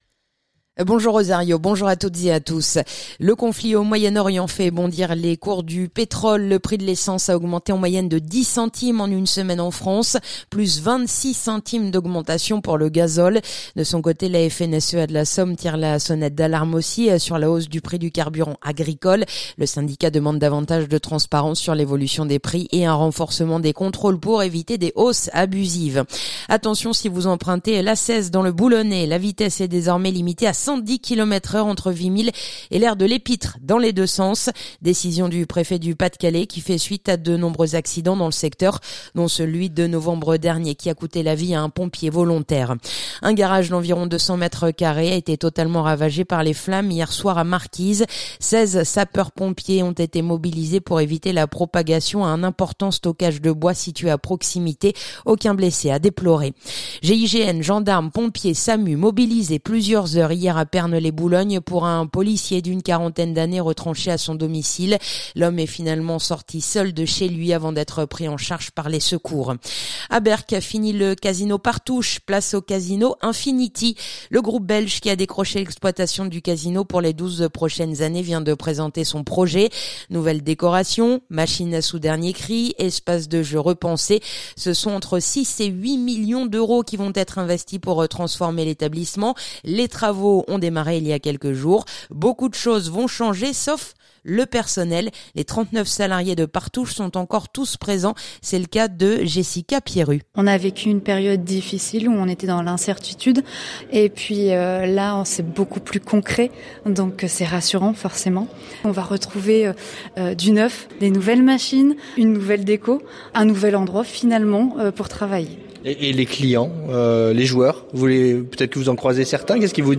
Le journal du samedi 7 mars